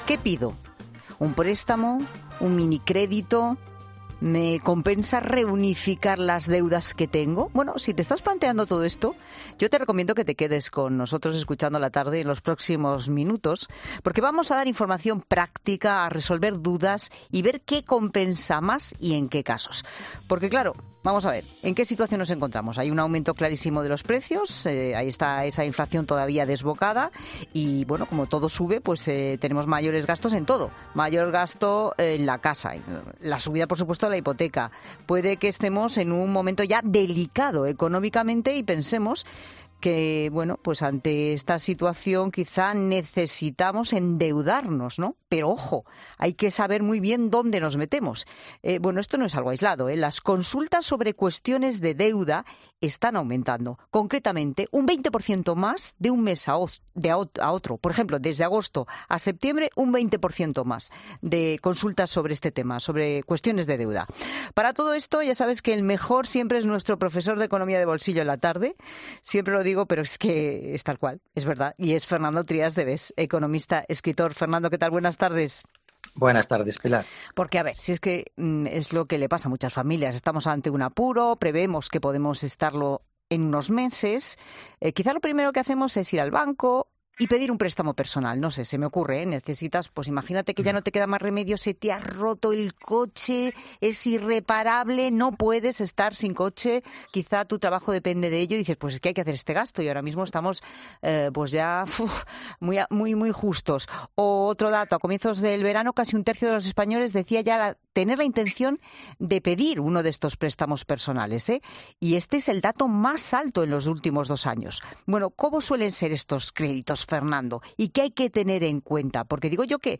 El economista Trías de Bes explica en COPE qué esconden los créditos rápidos y cuál sería el único caso para endeudarse ahora
Por eso, por los micrófonos de La Tarde ha pasado este miércoles el economista Fernando Trías de Bes , que ha resuelto todas estas dudas y consultas monetarias.